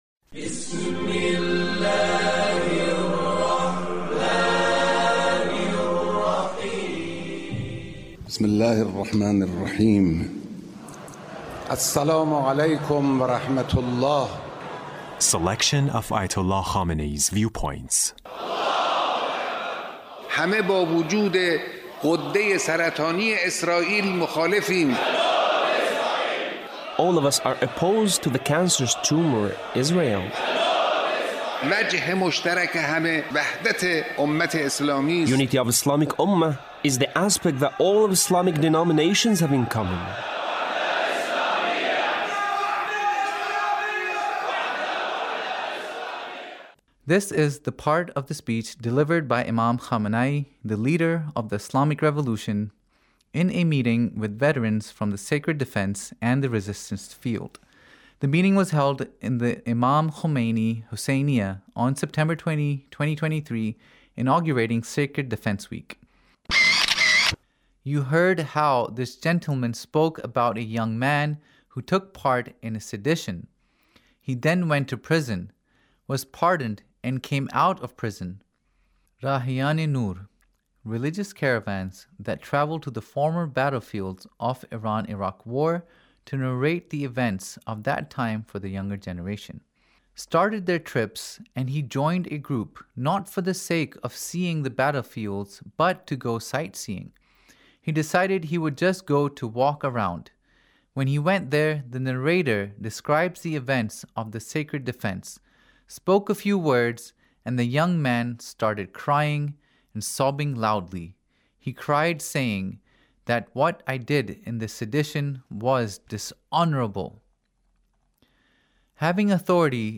Leader's Speech about Sacred defense